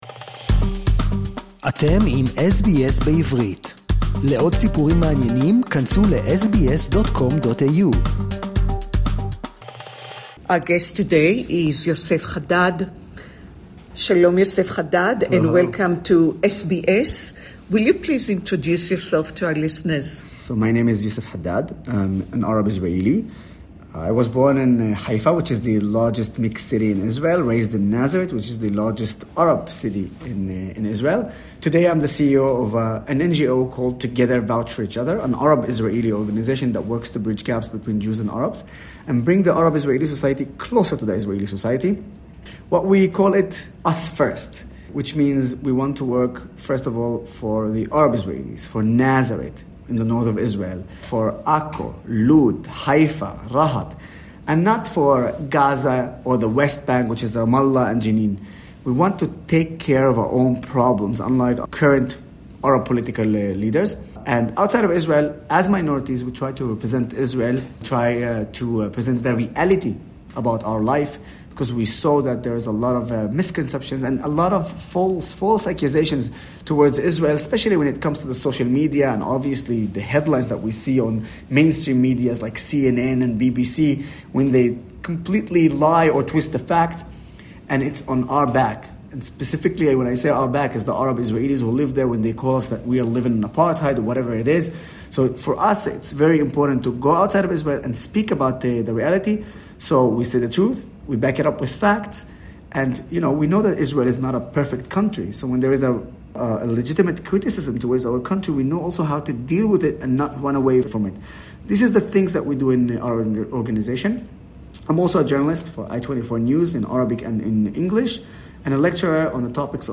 (Interview in English)